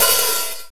RX OPEN HAT.wav